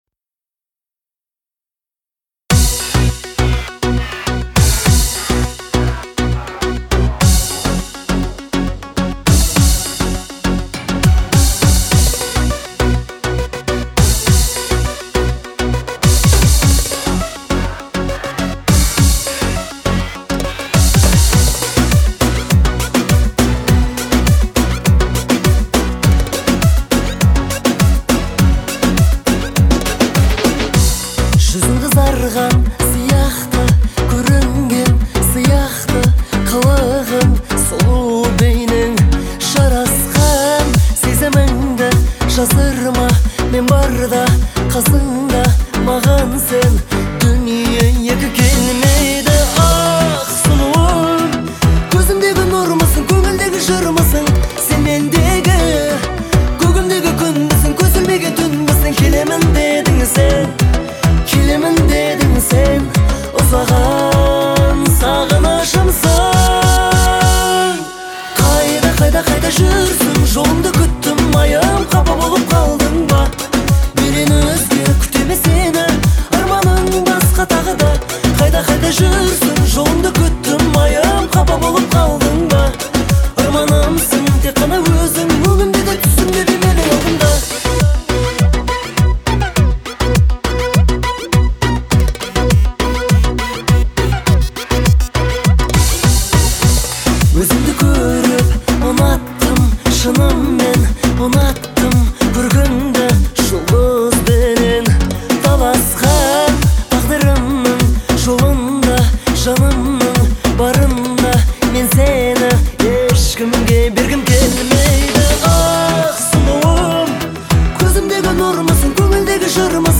мягким вокалом
мелодичными инструментами, что придаёт ей особую теплоту